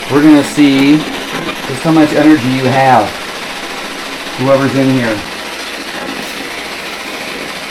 Spirit Box Clip 5
This again was recorded in the "bad" area of the basement, I had decided to "test" the spirits to see if any were around who were strong enough to come through at the 50 ms sweep rate.
You'll hear me challenege them to see if one is strong enough, and right away you'll hear a female voice speak what I believe is a three-word sentence directly responding to me.
Double CD Audio Quality I hear a female say, "Don't try it!" The last word is faint, but that's what I hear.